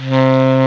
SOFT SAX 2.wav